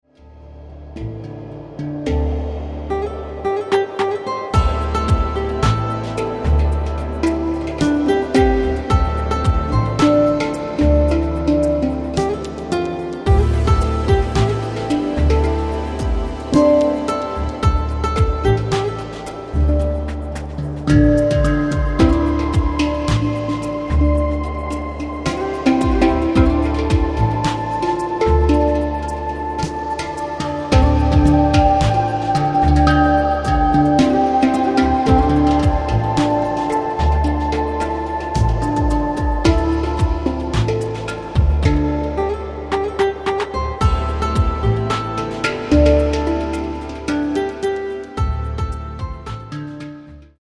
hang and programming